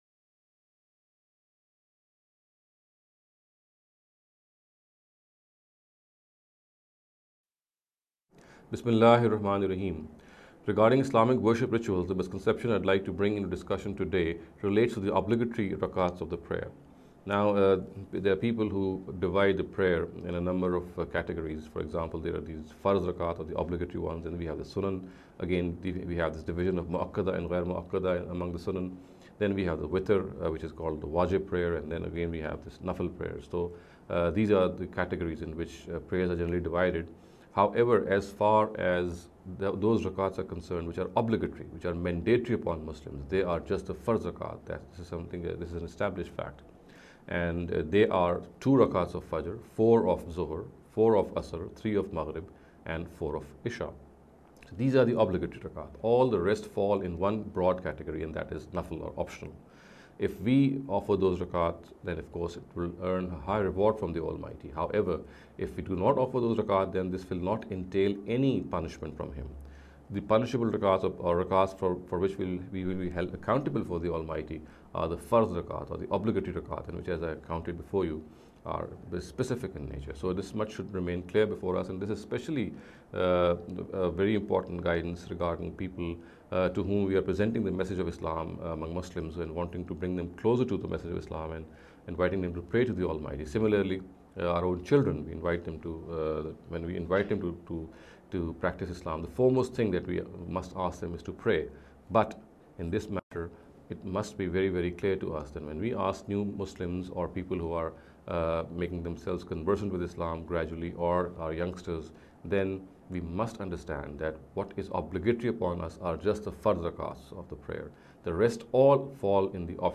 This lecture series will deal with some misconception regarding the Islamic Worship Ritual. In every lecture he will be dealing with a question in a short and very concise manner.